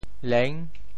佞 部首拼音 部首 亻 总笔划 7 部外笔划 5 普通话 nìng 潮州发音 潮州 lêng6 文 中文解释 佞〈动〉 (会意。